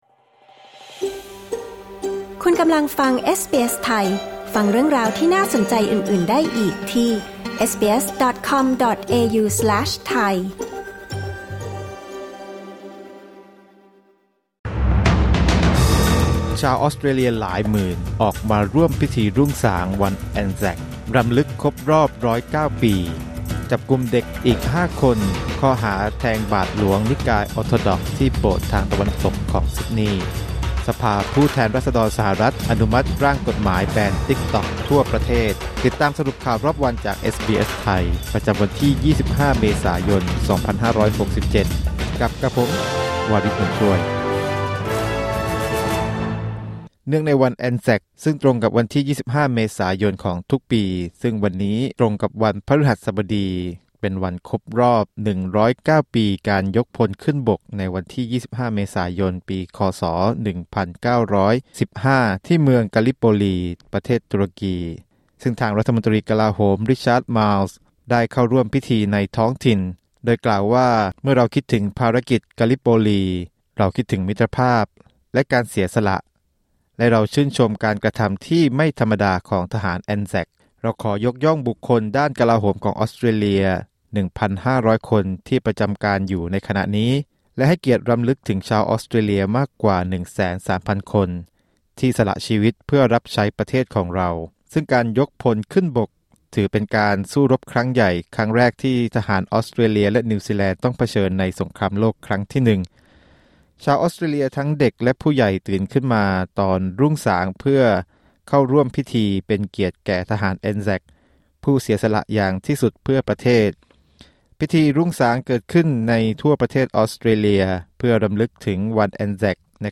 สรุปข่าวรอบวัน 25 เมษายน 2567